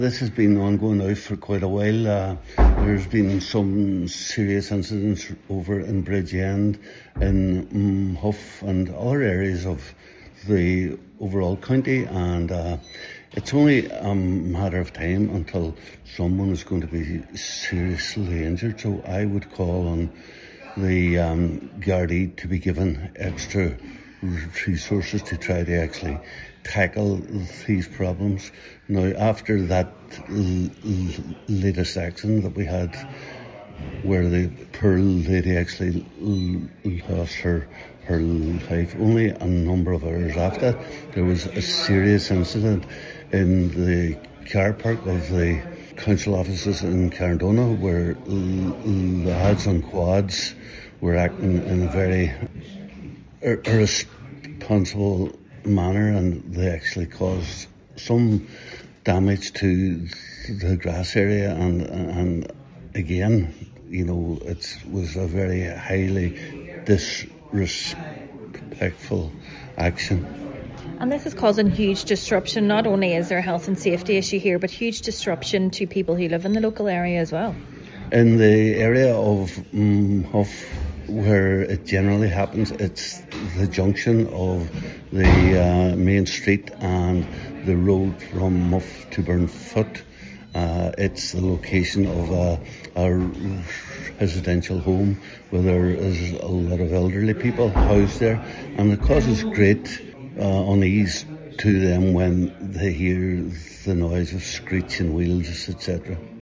Councillor Crossan says a more proactive approach is needed, as much disruption is being caused to local residents also: